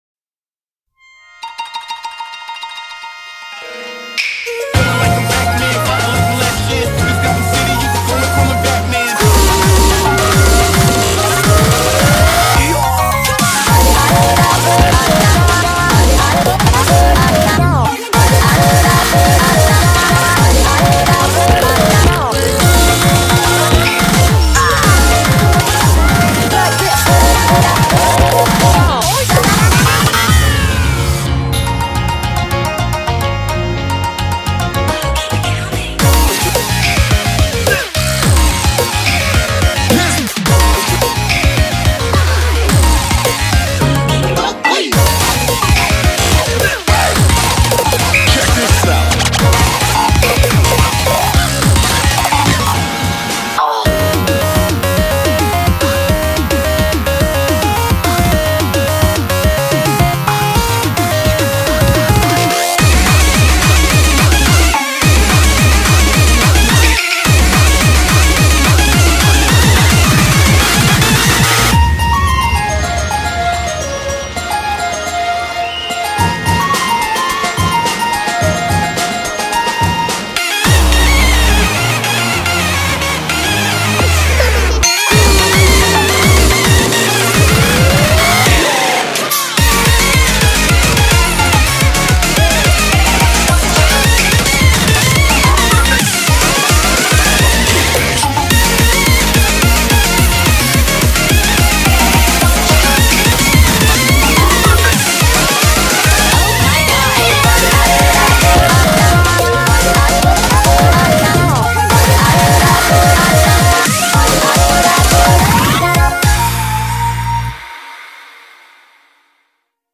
BPM215--1
Audio QualityPerfect (High Quality)